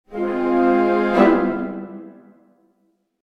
dramatic.wav